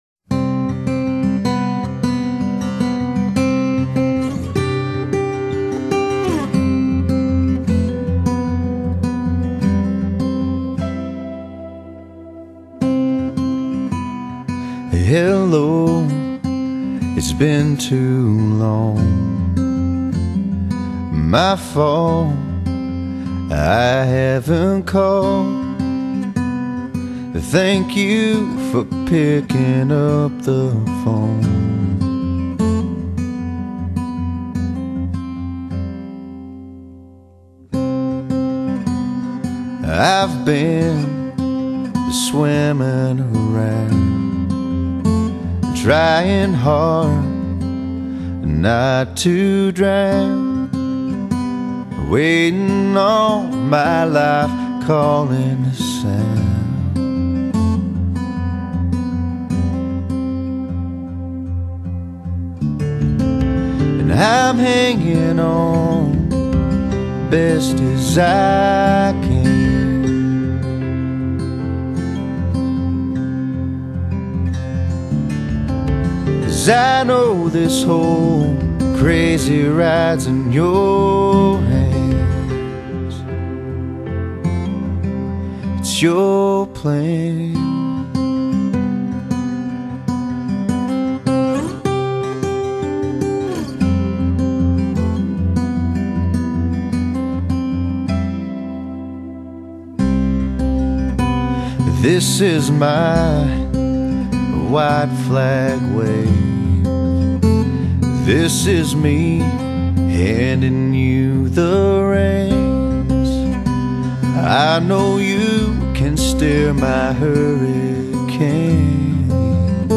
鄉村歌手
專輯主打當代鄉村搖滾，風格厚重；整張專輯雄厚大氣，優美萬分，充滿著濃濃的哀愁；